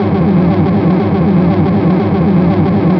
Future_engine_1_on.wav